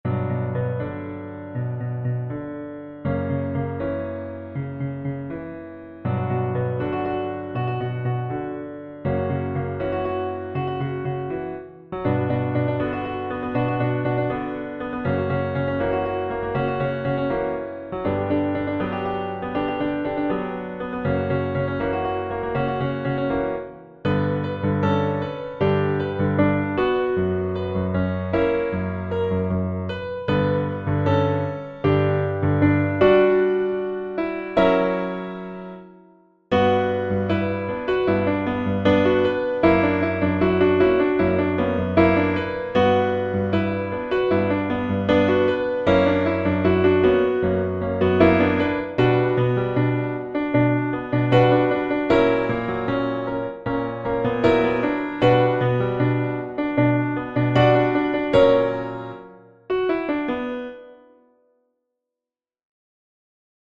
(SATBB choir) Listen PDF MIDI MP3 MuseScore